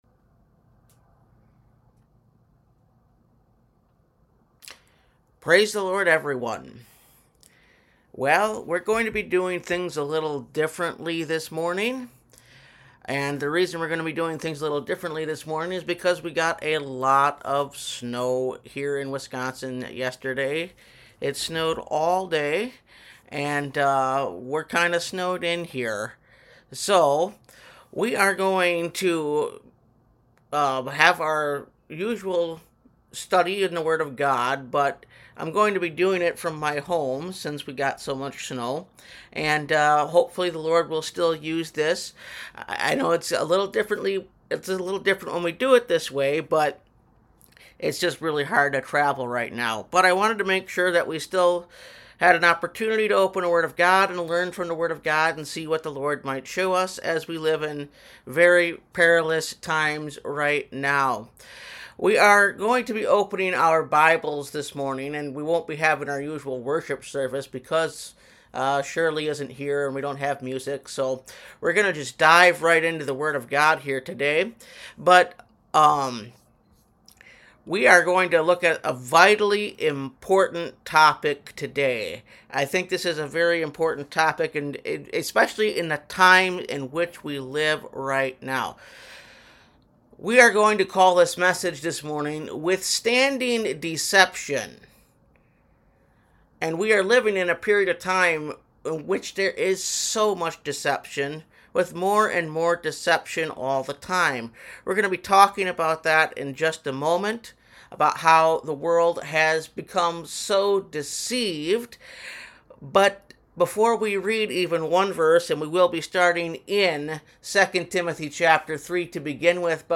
Withstanding Deception (Message Audio) – Last Trumpet Ministries – Truth Tabernacle – Sermon Library